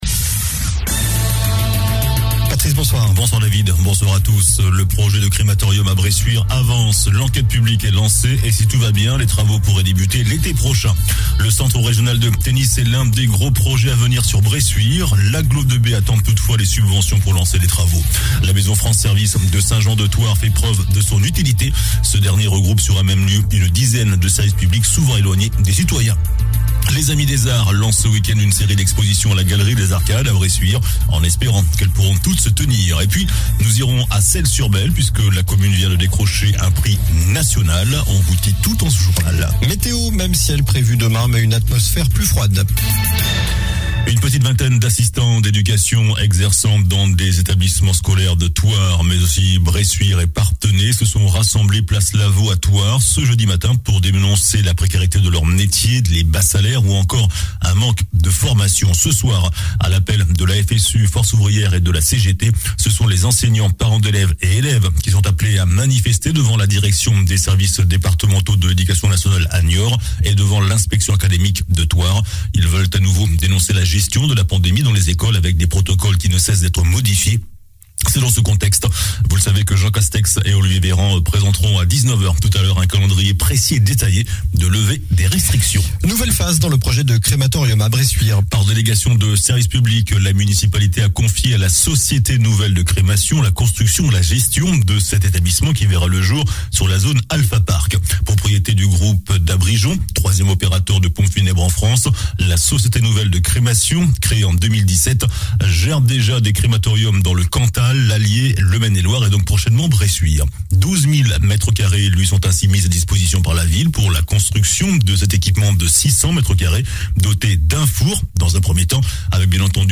JOURNAL DU JEUDI 20 JANVIER ( SOIR )